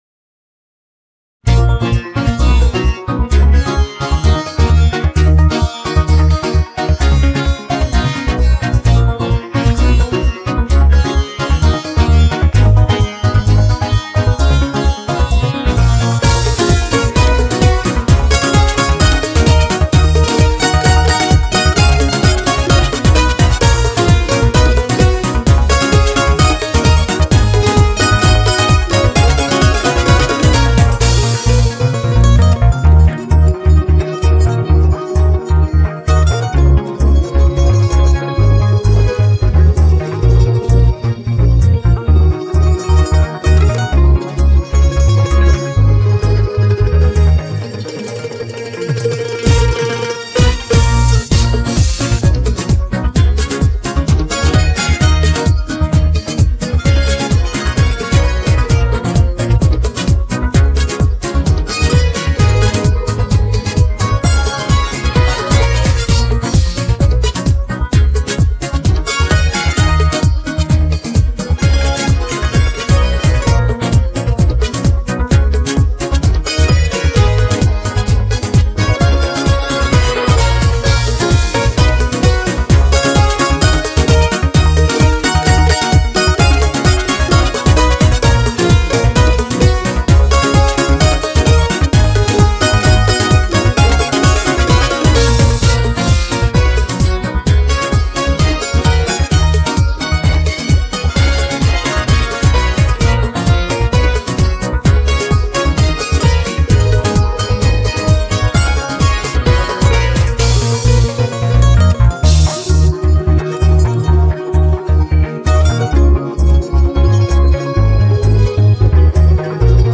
بیت بدون صدای خواننده